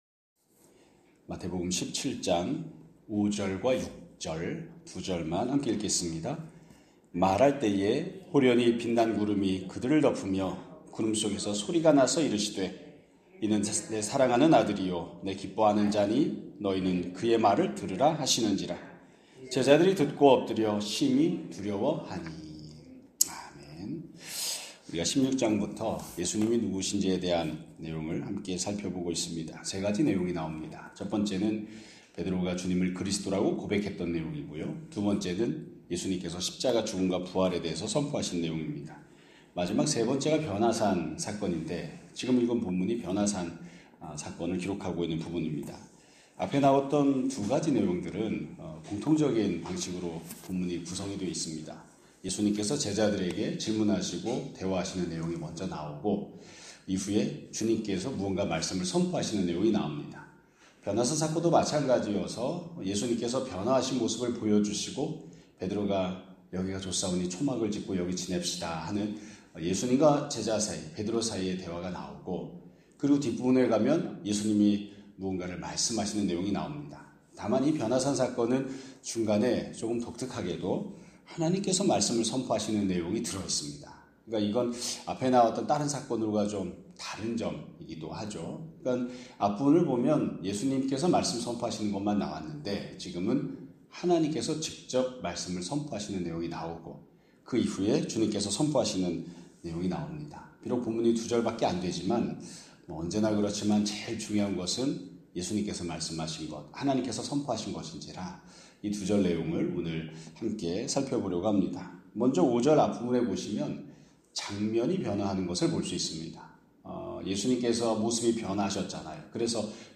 2025년 11월 24일 (월요일) <아침예배> 설교입니다.